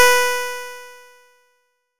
nes_harp_B4.wav